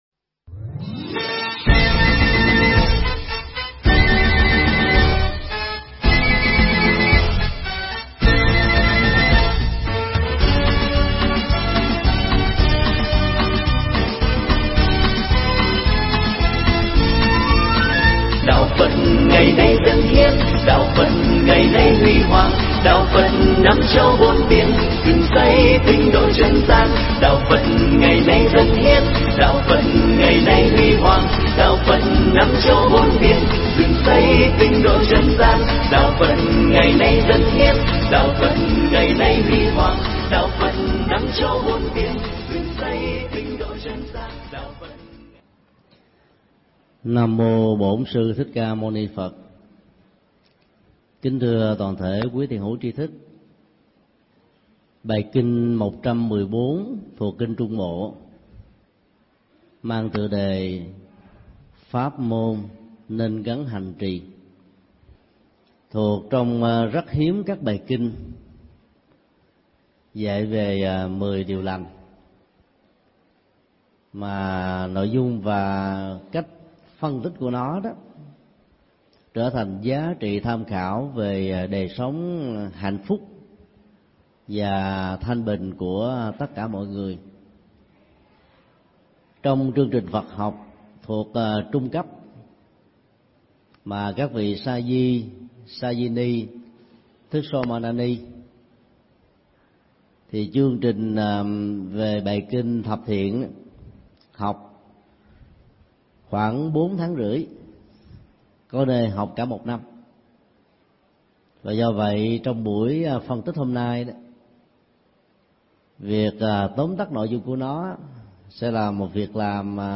Nghe mp3 Pháp Thoại Kinh Trung Bộ 114 (Kinh Nên Hành Trì, Không Nên Hành Trì) – Mười điều thiện - Thượng Tọa Thích Nhật Từ giảng tại Chùa Xá Lợi, ngày 16 tháng 11 năm 2008